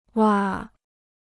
瓦 (wǎ): roof tile.